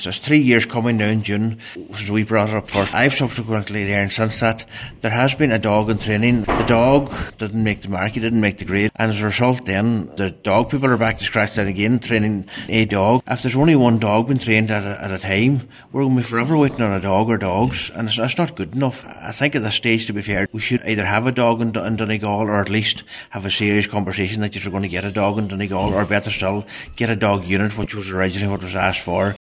Cllr McClafferty says the previous Joint Policing Committee asked three years ago for a dog to be based here, and it’s time the issue was revisited……